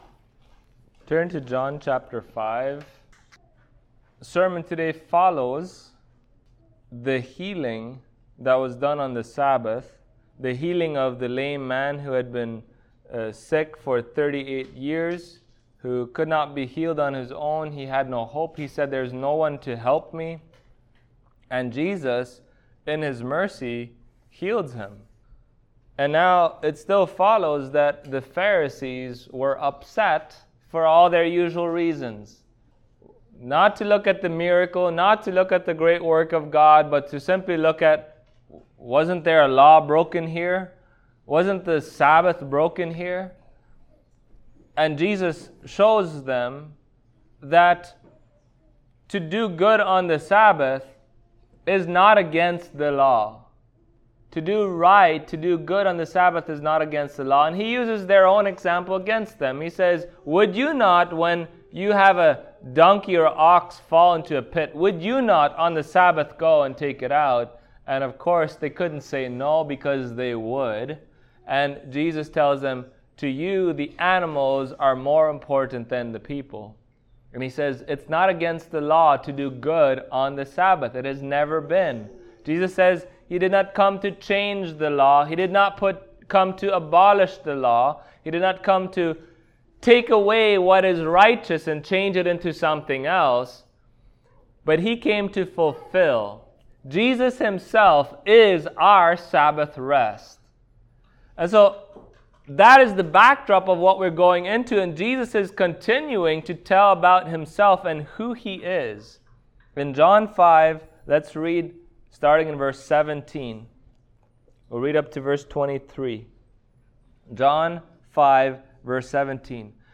John Passage: John5:17-23 Service Type: Sunday Morning Topics